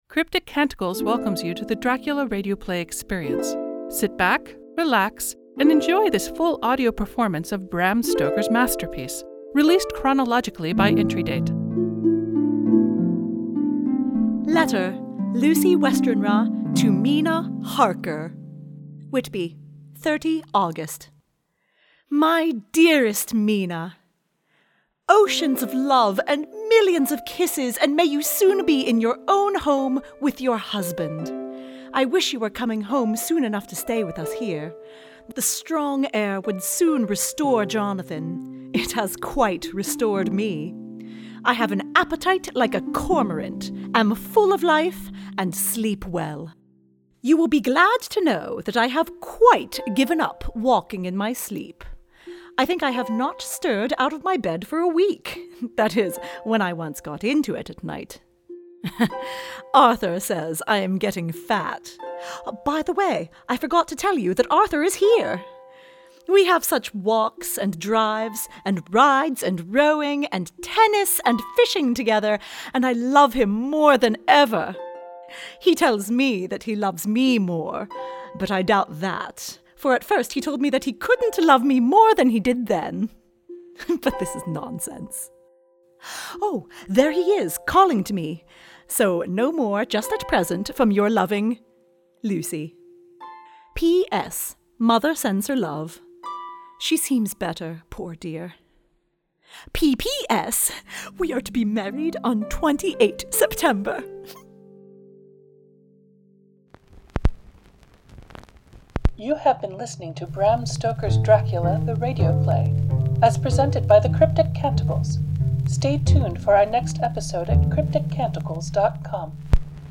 Audio Engineer, SFX and Music